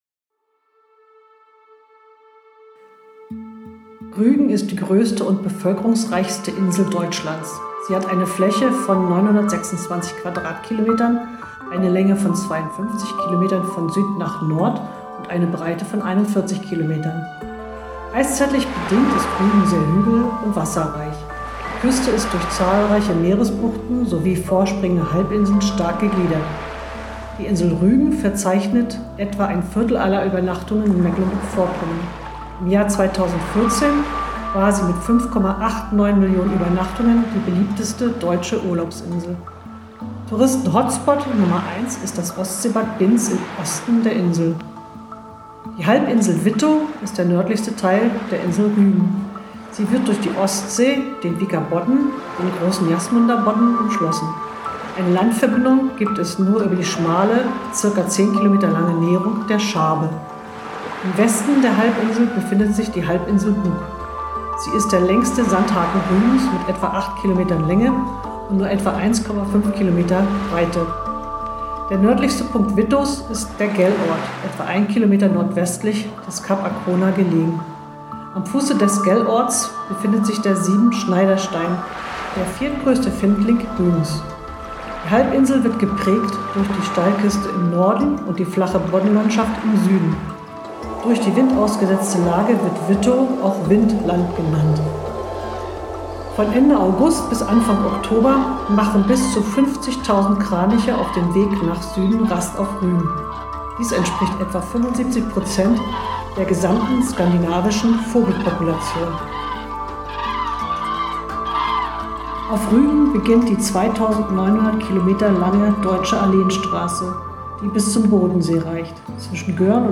Music by Lexin_Music from Pixabay